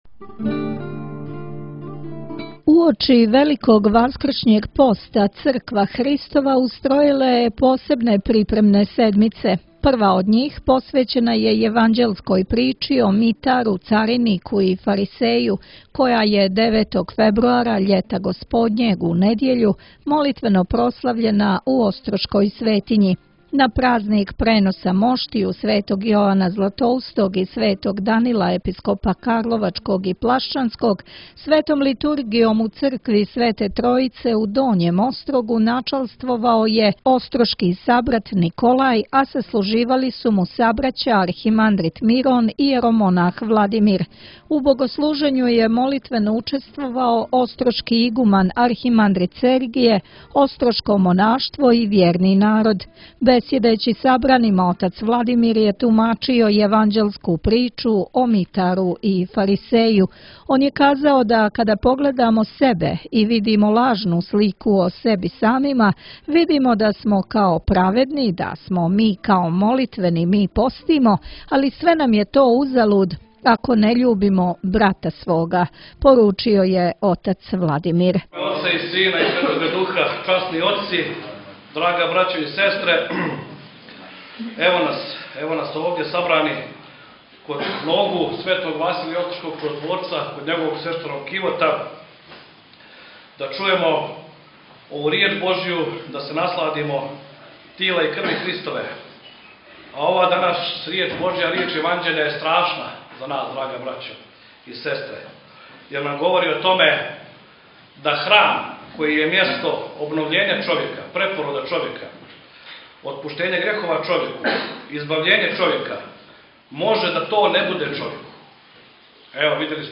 Епископ топлички Петар данас је служио свету Литургију у цркви Светог великомученика Димитрија на Новом Београду, поводом празника Света Три […]